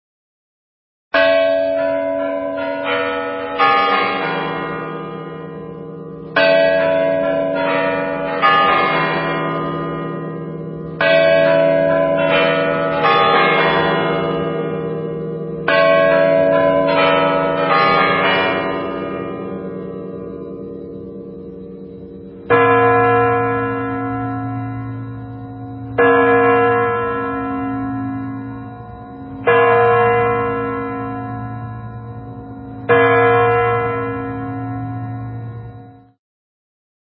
/32kbps) 16kbps (72.6кб) Описание: Бой Курантов.
kuranti.mp3.16.mp3